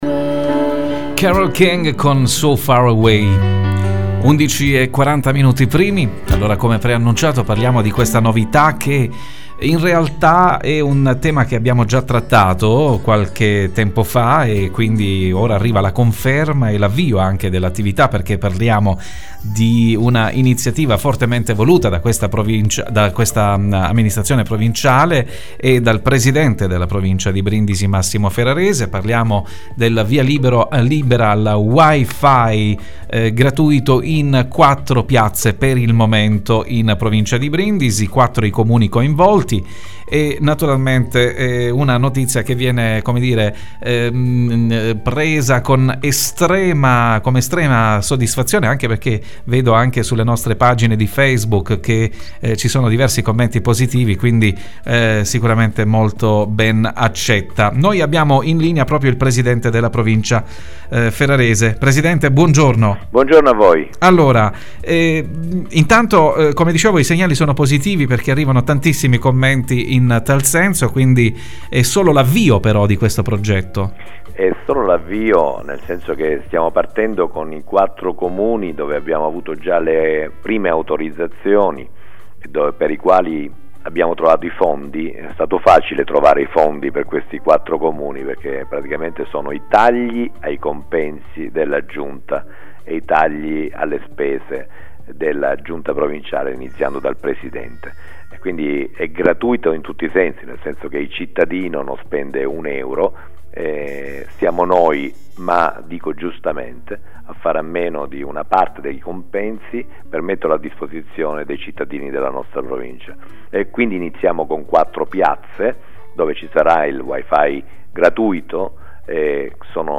Di seguito l’intervista di IDEA RADIO al Presidente della Provincia di Brindisi Massimo Ferrarese:
INTERVISTA AUDIO A MASSIMO FERRARESE
intervista-massimo-ferrarese.mp3